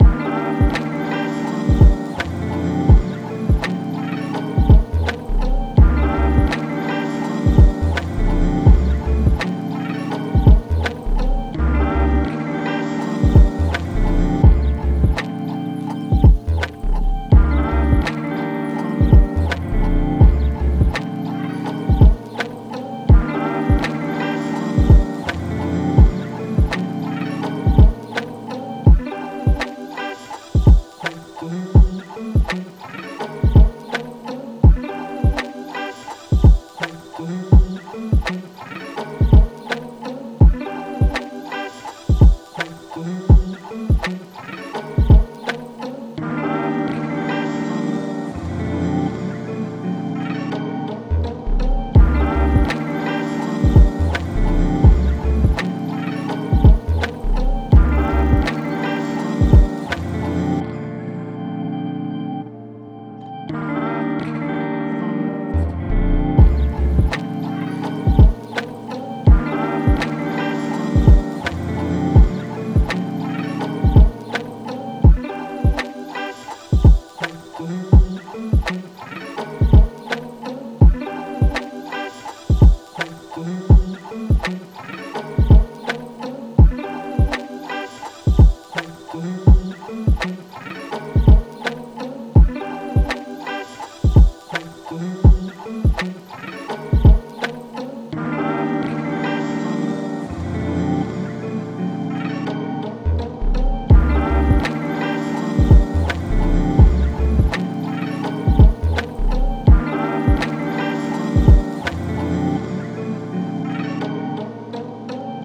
Before I sign off, I want to leave you with something different to check out a beat I made.